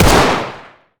fire-05.ogg